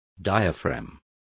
Complete with pronunciation of the translation of diaphragm.